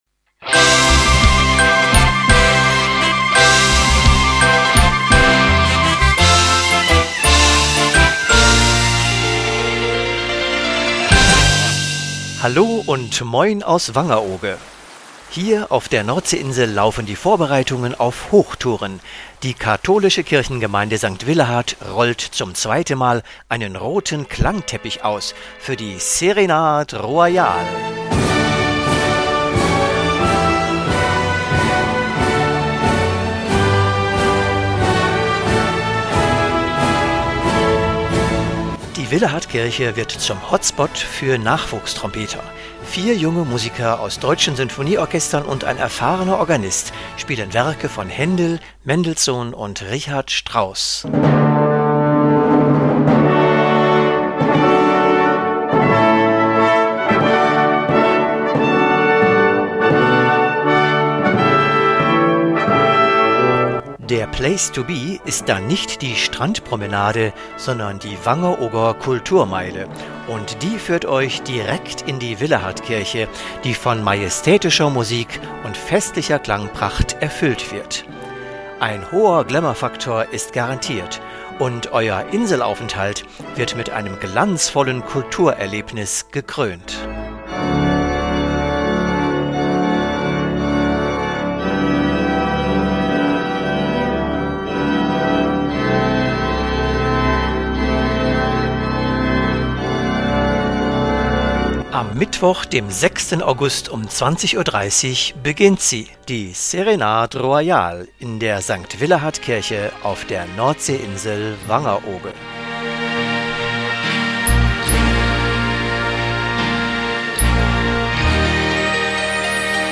Der rote Klangteppich ist ausgerollt für eine königliche Abendmusik. Die Willehadkirche wird zum glänzenden Hotspot für Nachwuchstrompeter. Vier junge Musiker aus Sinfonieorchestern in Schwerin, Dresden, Rostock und Göttingen, begleitet von festlicher Orgelmusik, spielen Werke von Händel, Mendelssohn, Scheidt, Anderson und Richard Strauss: majestätische Klangpracht mit garantiert hohem Glamour-Faktor bei freiem Eintritt.
serenadentrailer.mp3